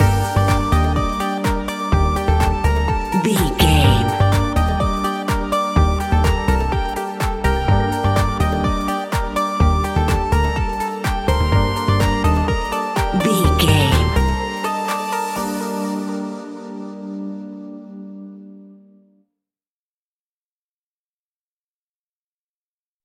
Aeolian/Minor
groovy
uplifting
driving
energetic
drum machine
synthesiser
bass guitar
funky house
electronic
upbeat